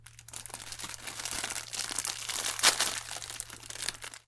关于硬塑料压缩音效的PPT演示合集_风云办公